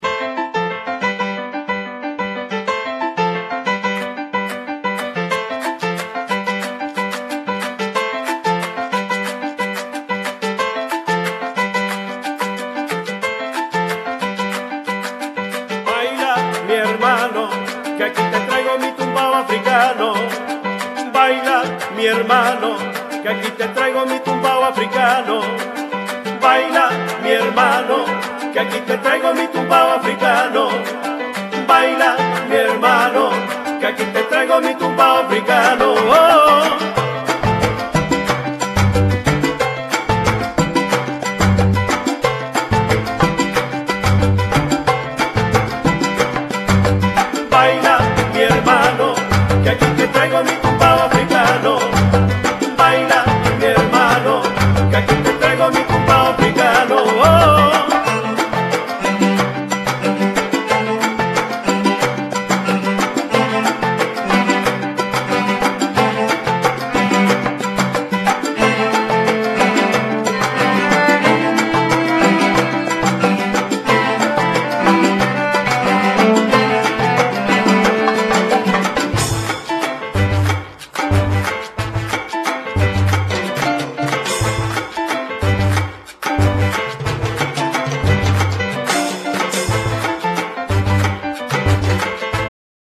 Genere : Pop LATINO